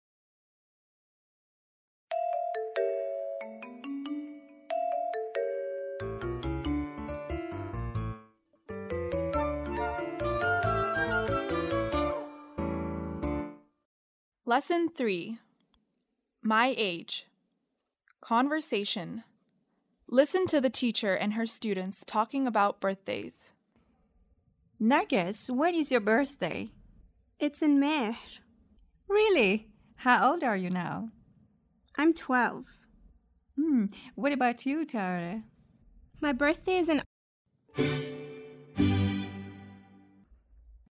7-Lesson3-Conversation.mp3